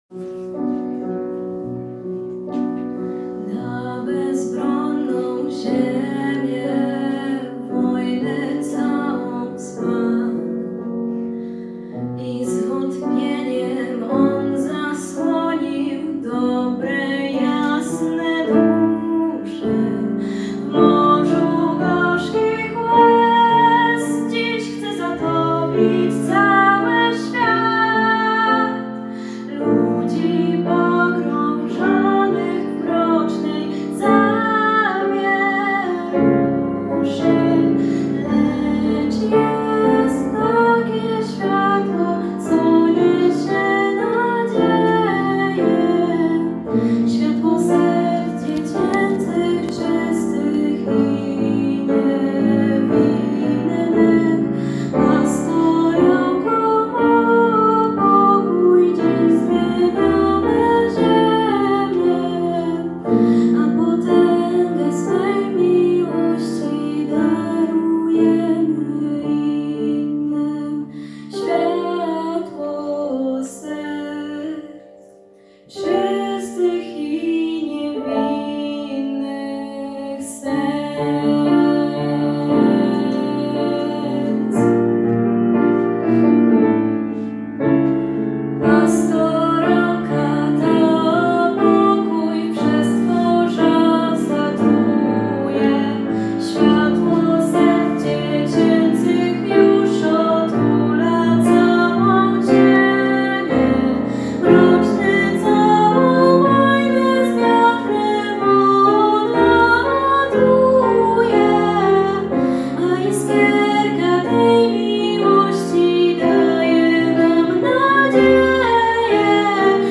Zajebisty utwór będę grał jako akompaniator z majne wokalistką - Coś co leży mi w palcach a jednocześnie brzmi świetnie, no po prostu z całego serducha lowciam ten track :D.
Już zagrane - wyszło tak jak miało wyjść :), wszystko na żywca itd więc za me wszelkie błędy sorry ;), dla zainteresowanych podsyłam.
Wiem, na próbach bywało lepiej ale stres +to że występuje się na live zrobiło swoje, nadal jednak uważam to za piękny utworek :).